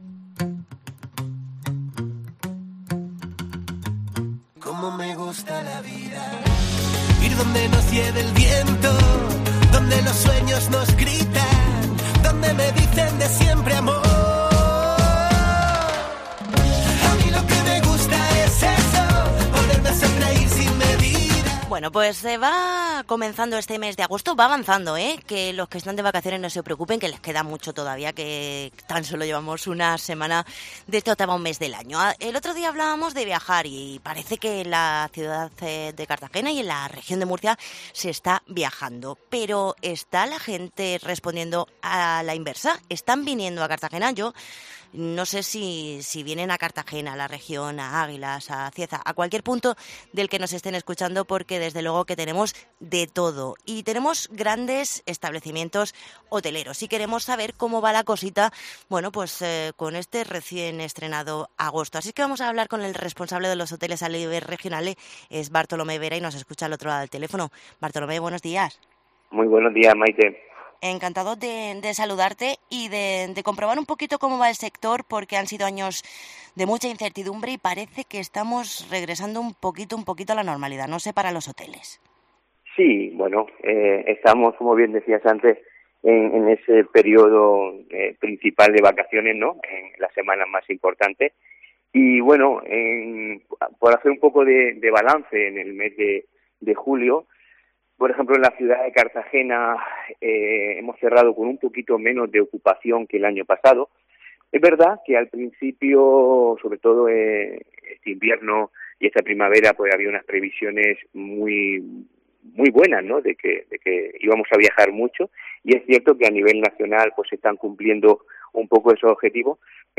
Entrevista con el responsable regionales de los Hoteles